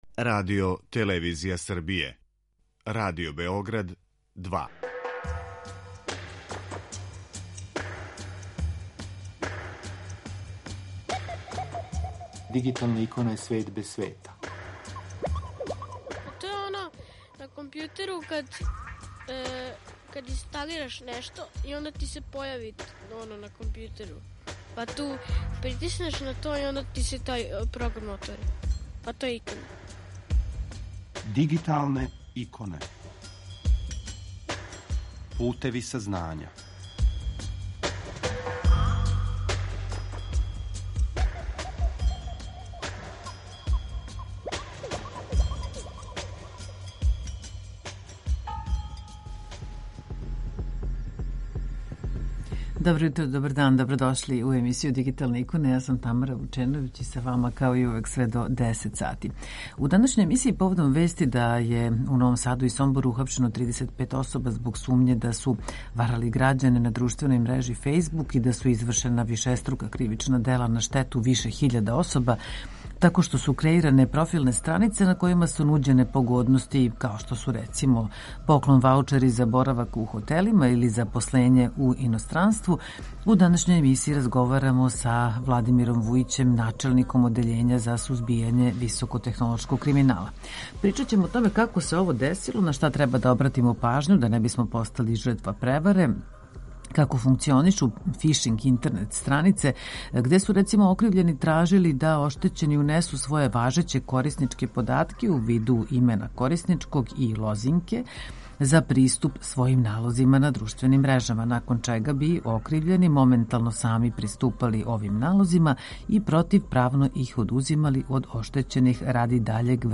Поводом вести да је у Новом Саду и Сомбору ухапшено 35 особа због сумње да су варали грађане на друштвеној мрежи Фејсбук и да су извршена вишеструка кривична дела на штету више хиљада особа тако што су креиране различите профилне странице на којима су нуђене погодности као што су запослење у иностранству, поклон ваучери за боравак у хотелима итд., у данашњој емисији разговарамо са Владимиром Вујићем, начелником Одељења за сузбијање високотехнолошког криминала.